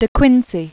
(dĭ kwĭnsē, -zē), Thomas 1785-1859.